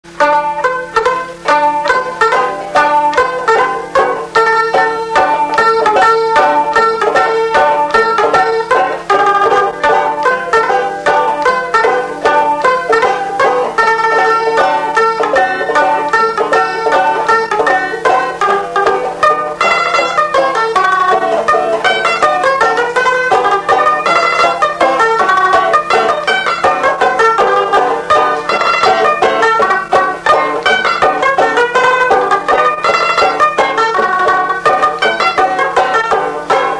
Instrumental
danse : mazurka
Pièce musicale inédite